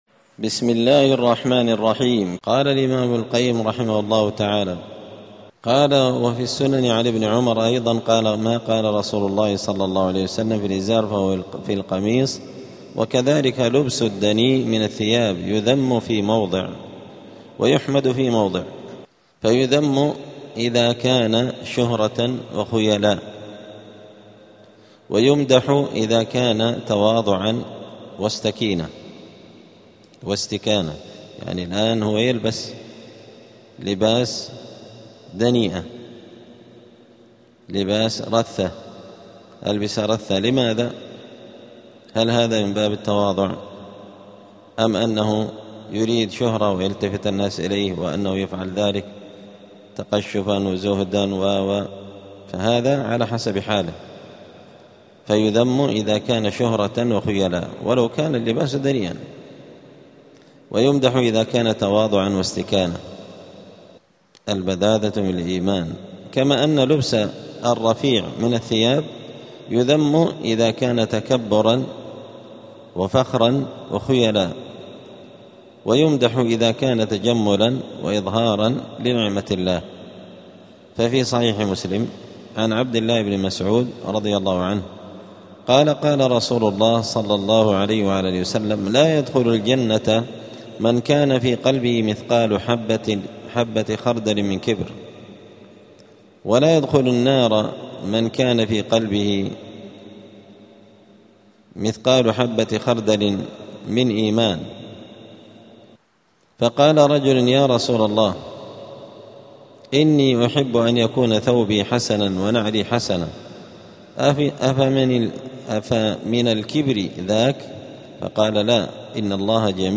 *الدرس السادس والعشرون (26) {ﻓﺼﻞ ﻓﻲ ملابسه ﺻﻠﻰ اﻟﻠﻪ ﻋﻠﻴﻪ ﻭﺳﻠﻢ}.*
دار الحديث السلفية بمسجد الفرقان قشن المهرة اليمن